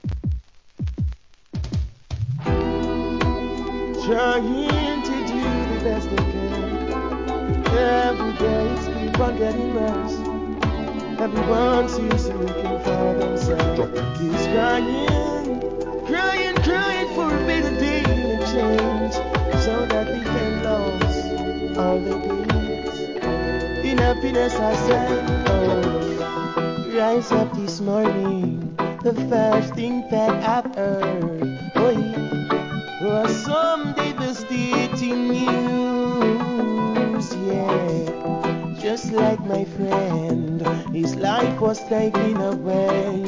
REGGAE
悲しげな哀愁のリディム